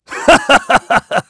Clause_ice-Vox_Happy3.wav